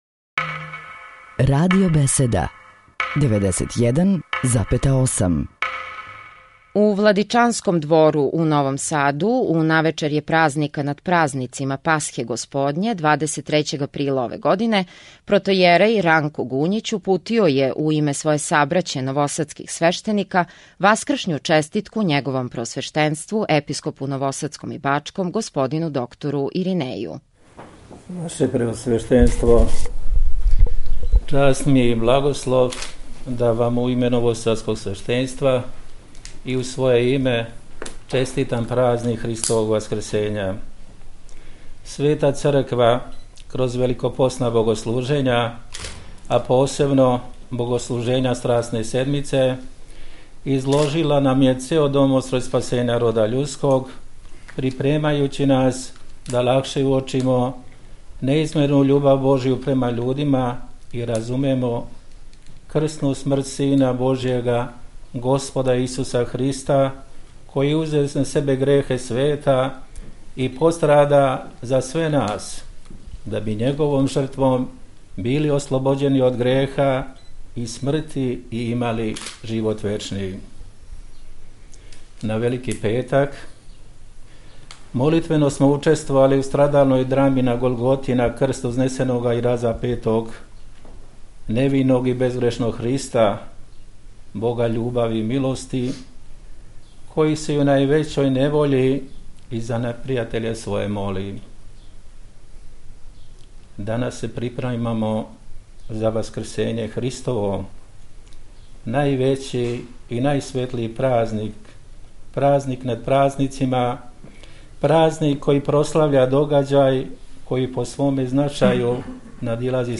У Владичанском двору у Новом Саду